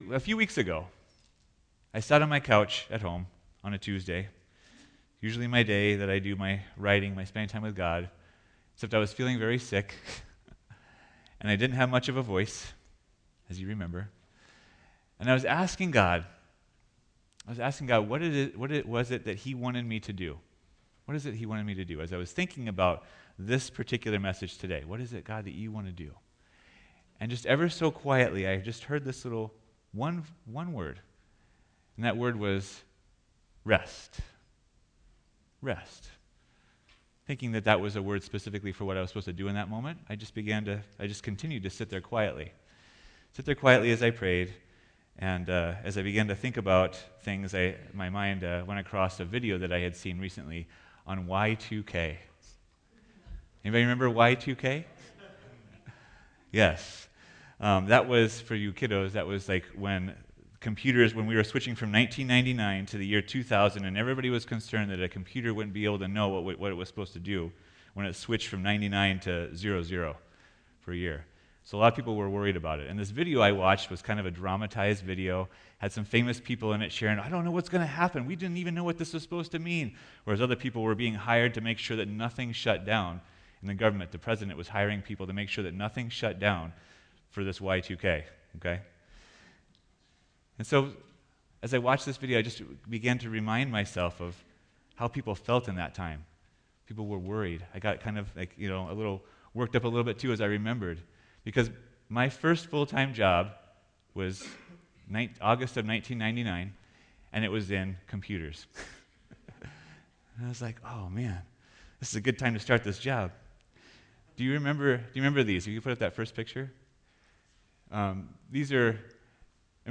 Listen in as we begin 2023 with a message on finding rest.